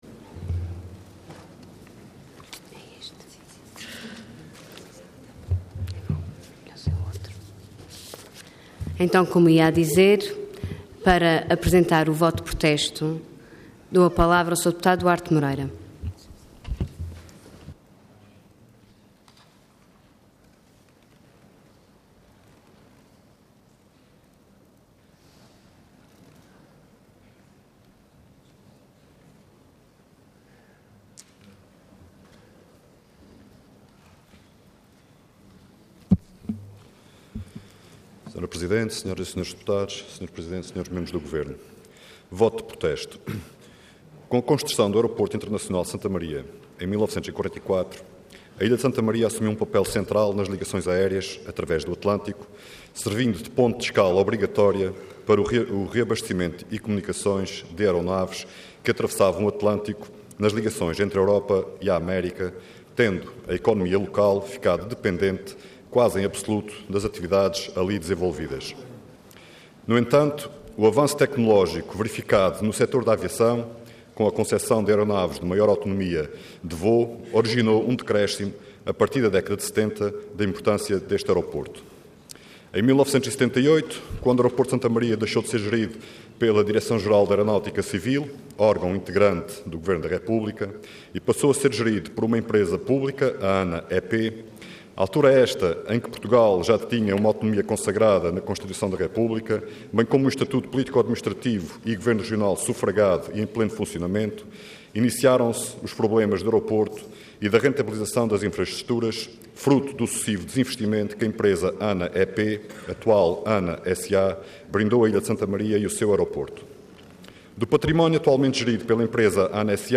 Intervenção Voto de Protesto Orador Duarte Moreira Cargo Deputado Entidade PS